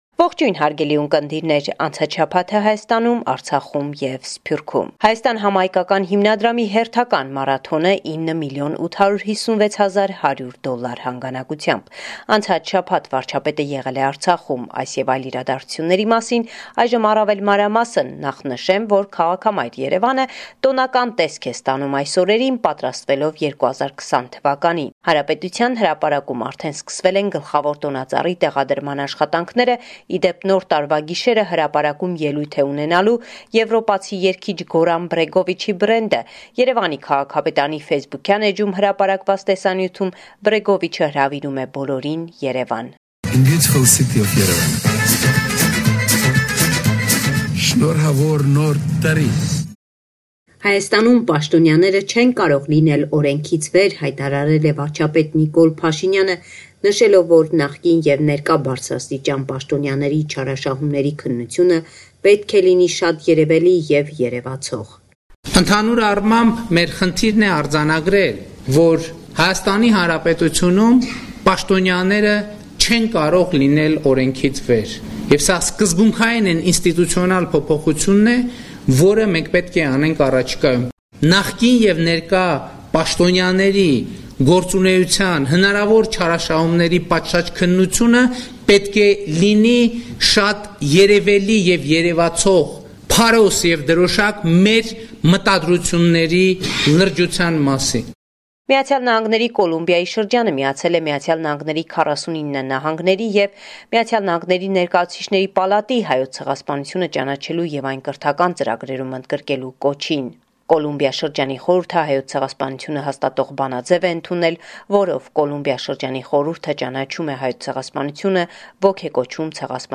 Վերջին լուրերը Հայաստանէն - 03/12/2019